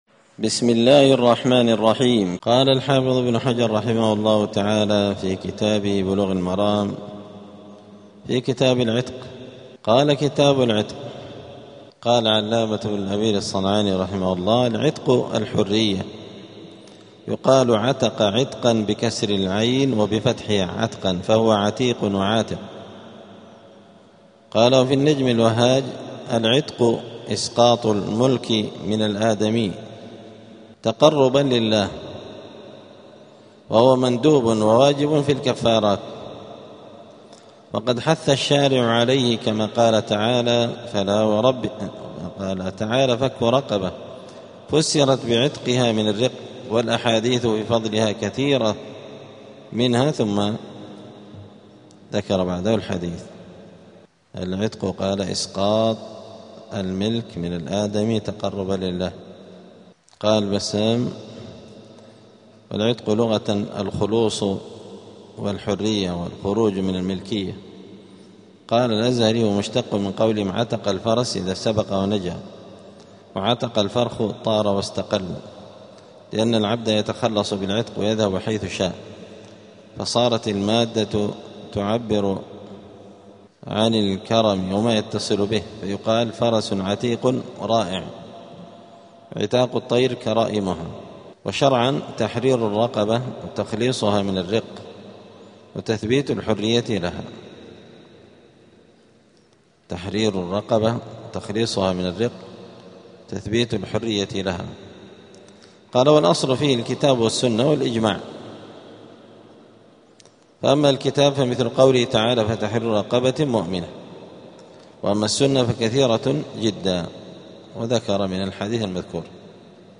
*الدرس الأول (1) {الترغيب في العتق}*